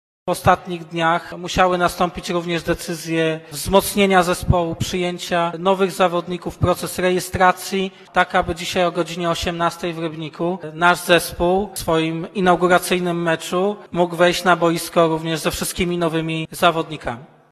Podpisywanie kontraktów z nowymi zawodnikami miało miejsce dopiero w tym tygodniu, w związku z zawirowaniami w Piłkarskiej Spółce Akcyjnej, zamieszaniem z trenerami i zmianie prezesa. Mówił o tym na dzisiejszej sesji prezydent Lucjusz Nadbereżny